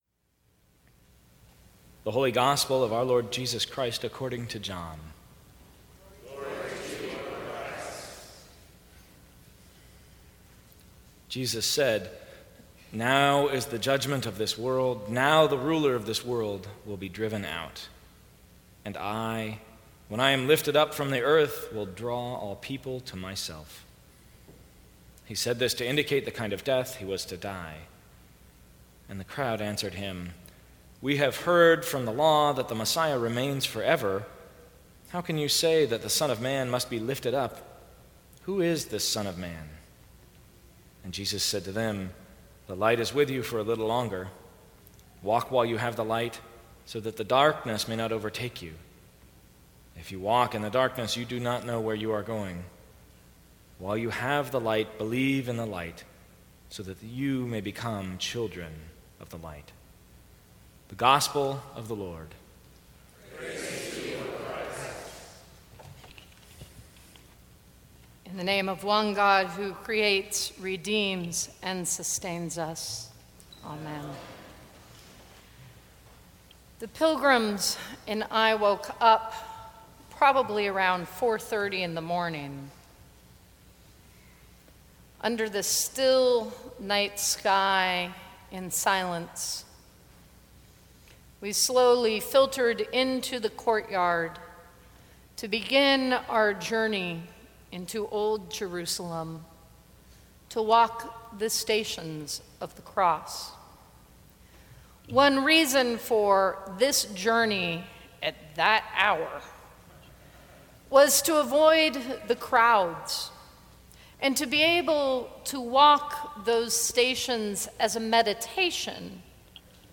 Sermons from St. Cross Episcopal Church Traveling Far and Well Sep 18 2016 | 00:13:41 1x 00:00 / 00:13:41 Subscribe Share Apple Podcasts Spotify Overcast RSS Feed Share Link Embed